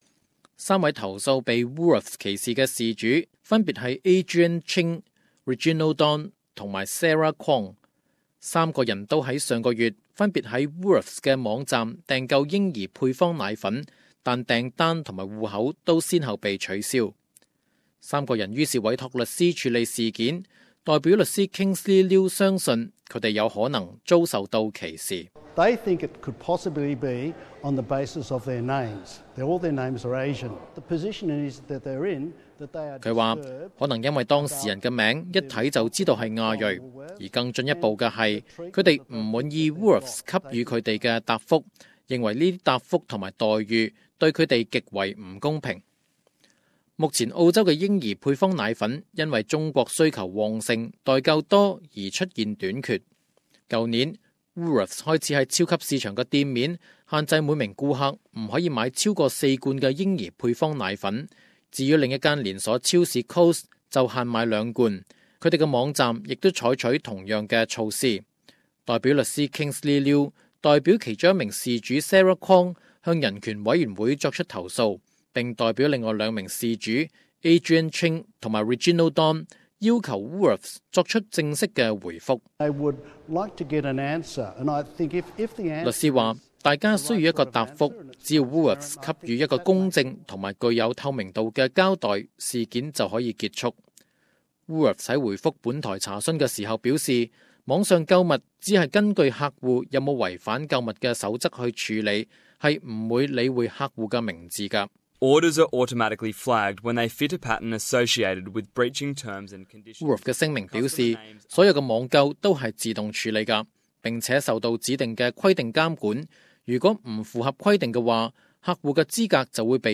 有华裔顾客投诉连锁超级巿场Woolworths，无故取消他们的网上户口，怀疑是因为他们拥有亚裔的姓名，并且买婴儿配方奶粉。本台采访了事件中的三名事主